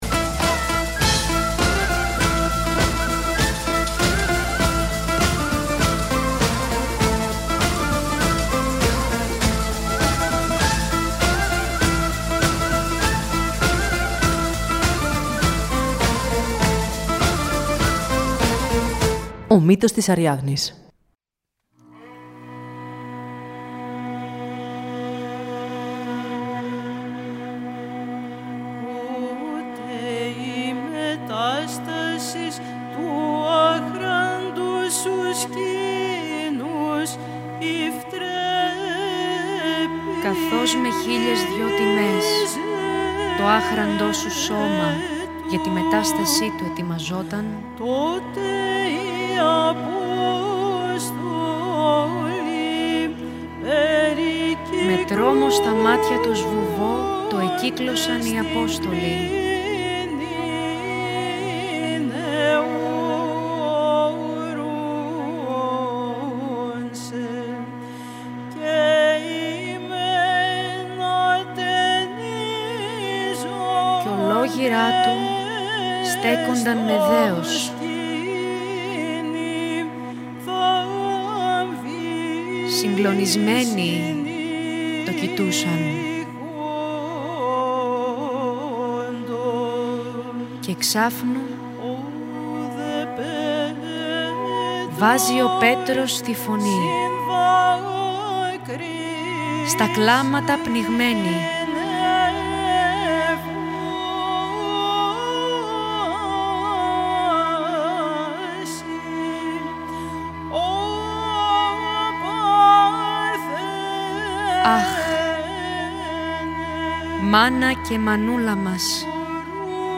Αυτή την Παρασκευή 15 Αυγούστου 2025, γιορτάζουμε την ανάληψη της Παναγίας στους ουρανούς, με χαρμόσυνες μελωδίες από τους «In Excelsis Orchestra» και «Σύναγμα» σε μέρος της μουσικής παράστασης «Χαράς Αιτία» σε ένα ηχητικό τοπίο που συνδυάζει μοναδικά, την ιερότητα της παράδοσης με τη ζωντάνια της σύγχρονης καλλιτεχνικής ματιάς.